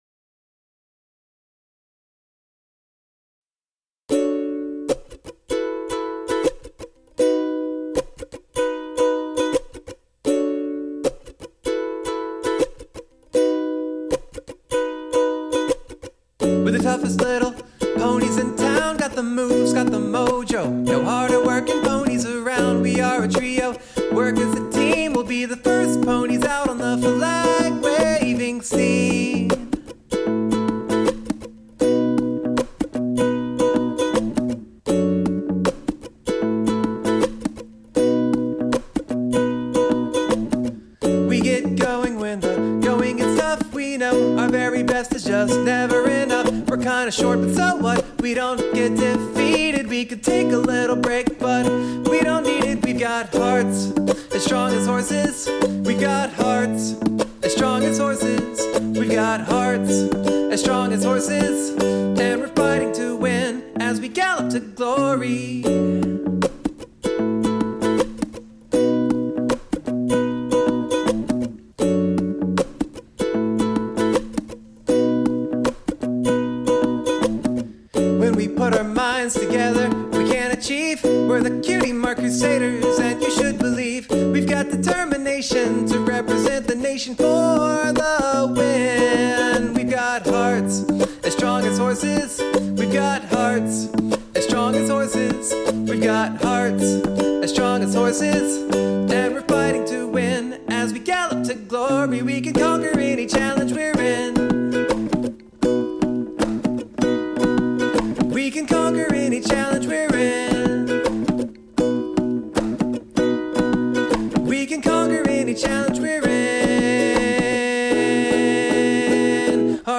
My first attempt at an acoustic cover.